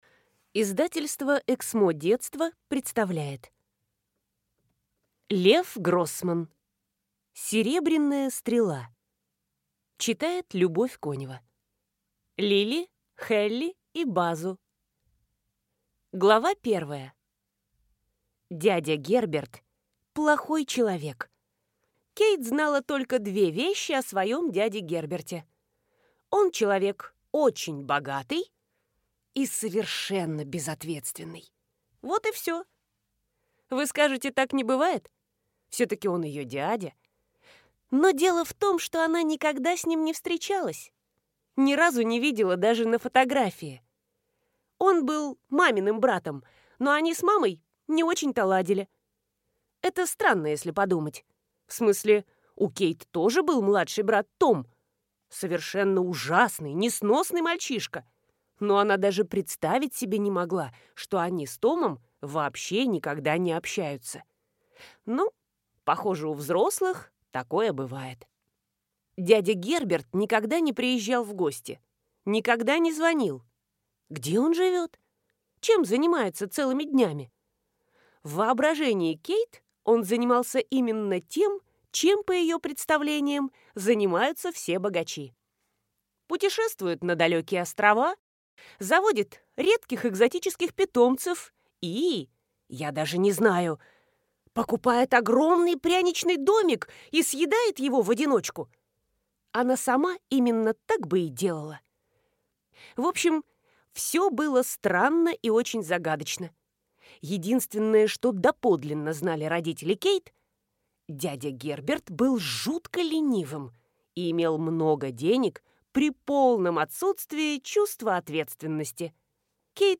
Аудиокнига Серебряная Стрела | Библиотека аудиокниг